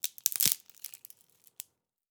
Bone_Break_1.wav